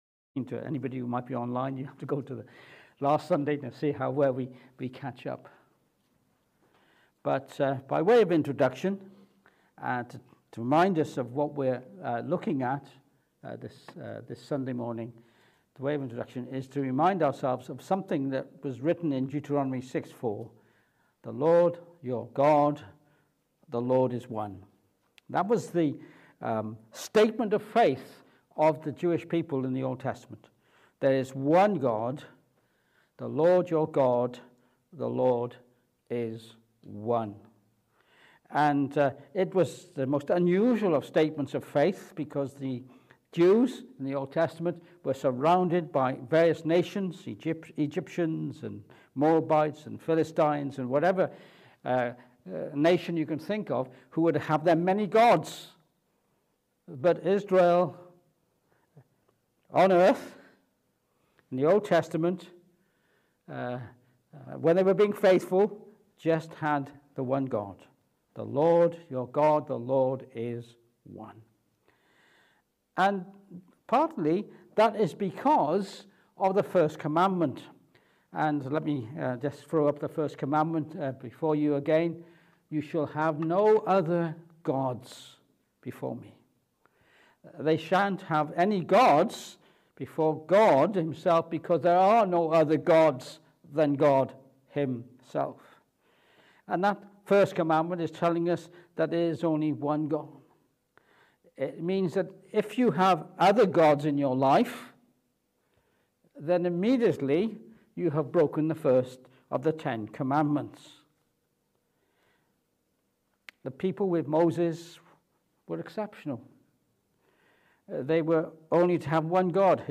Exodus 20:3 Service Type: Morning Service We continue to consider the first of the Ten Commandments